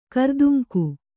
♪ karduŋku